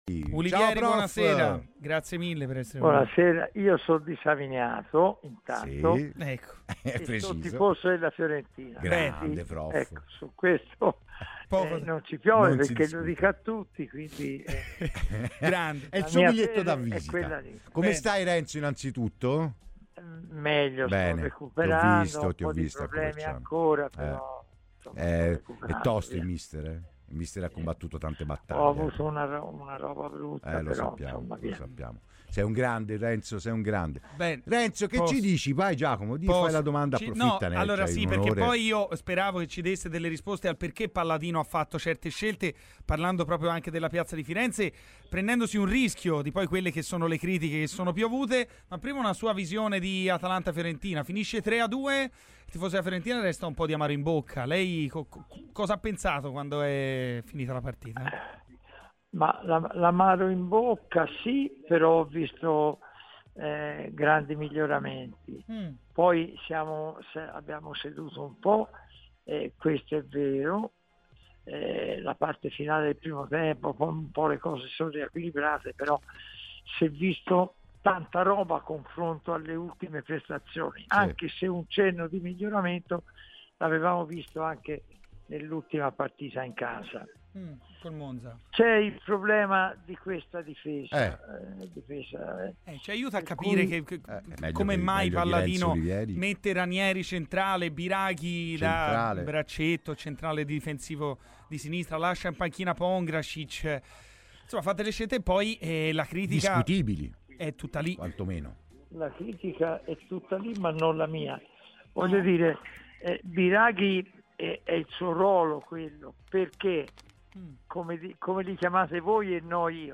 Il presidente dell'associazione italiana allenatori Renzo Ulivieri è intervenuto a Radio FirenzeViola durante la trasmissione "Garrisca al Vento" per parlare delle tematiche d'attualità di casa Fiorentina.